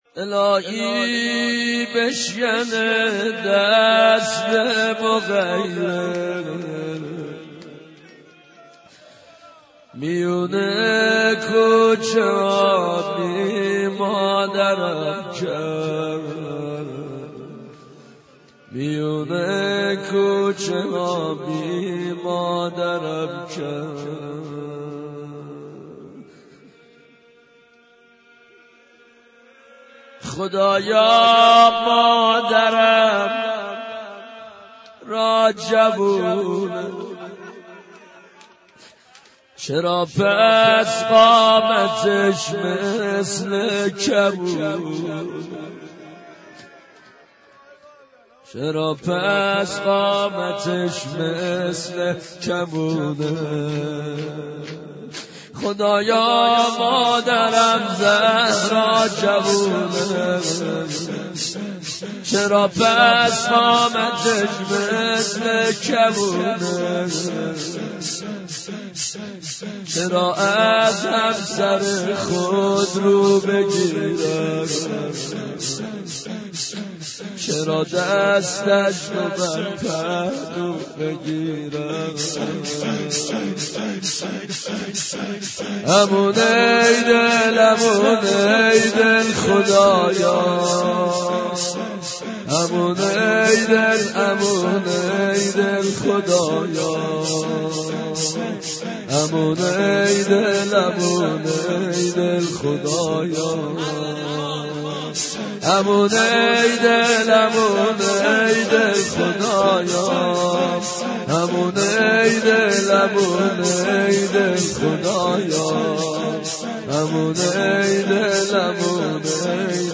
دانلود مداحی بی مادر شدم - دانلود ریمیکس و آهنگ جدید